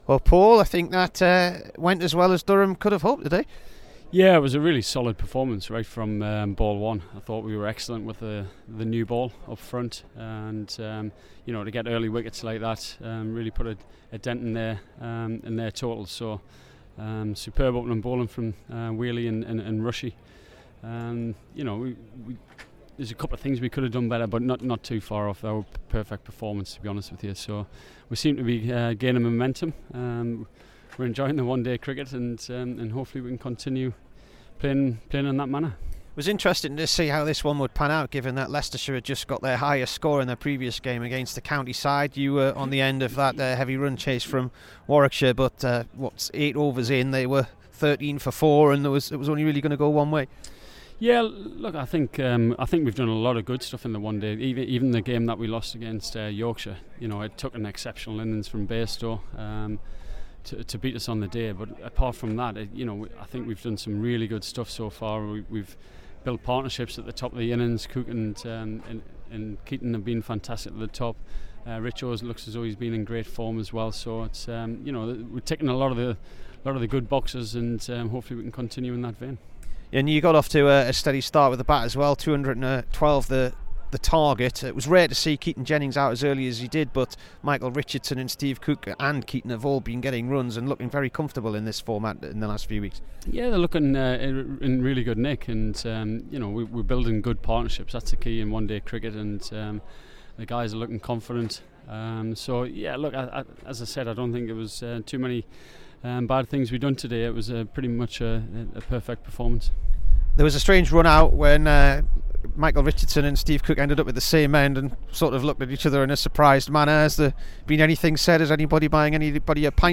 PAUL COLLINGWOOD INT
PAUL COLLINGWOOD TALKS TO BBC NEWCASTLE AFTER HIS MATCH-WINNING 65 V LEICESTERSHIRE IN THE ONE DAY CUP AT SOUTH NORTH CC.